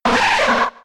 Cri de Nidorina K.O. dans Pokémon X et Y.